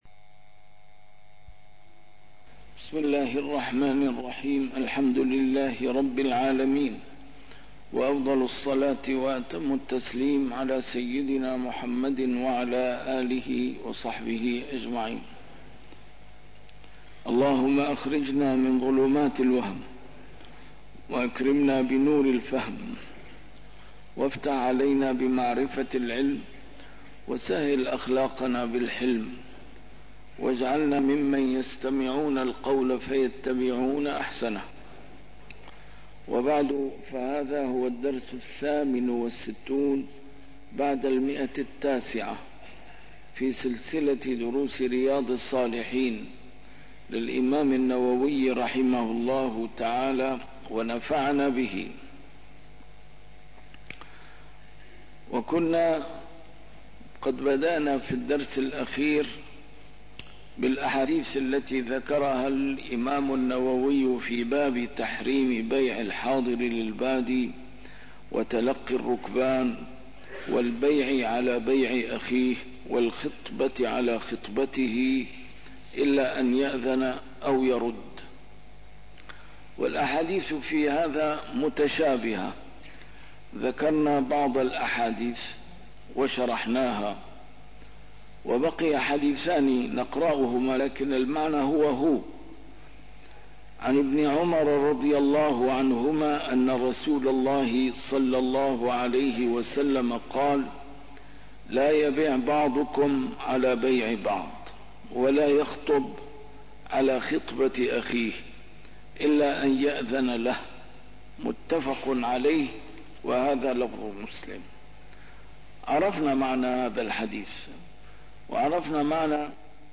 A MARTYR SCHOLAR: IMAM MUHAMMAD SAEED RAMADAN AL-BOUTI - الدروس العلمية - شرح كتاب رياض الصالحين - 968- شرح رياض الصالحين: تحريم بيع الحاضر للبادي - النهي عن إضاعة المال